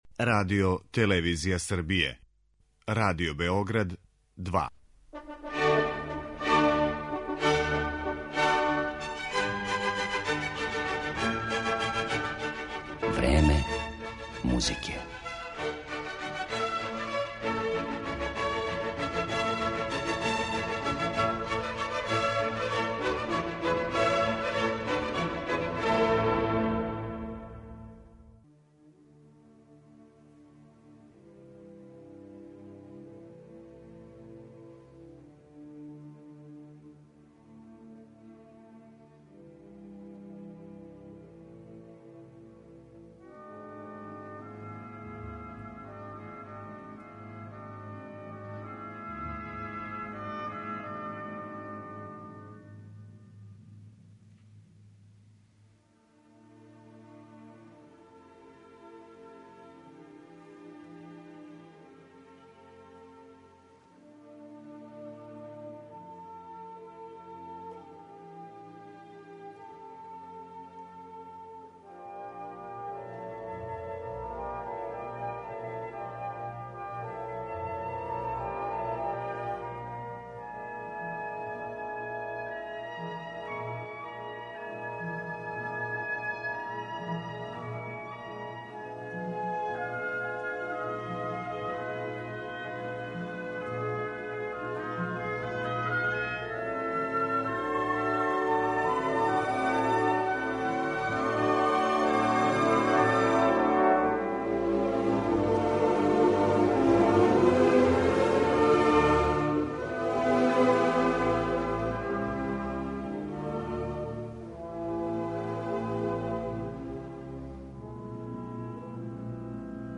Данашња емисија Време музике доноси фрагменте из композиција ових аутора у извођењу врхунских оркестара и вокалних солиста.